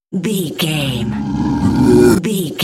Creature riser horror
Sound Effects
In-crescendo
Atonal
ominous
suspense
eerie
roar